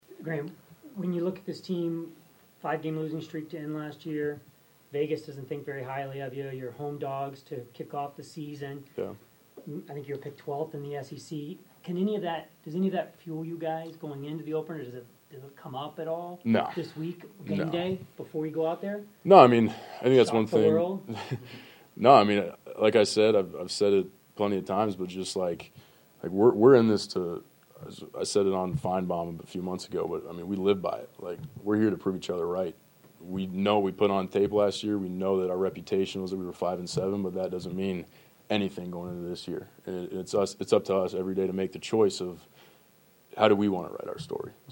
Florida quarterback Graham Mertz previewed a new season of Gator football and the opening matchup against No. 19 Miami in a news conference Monday.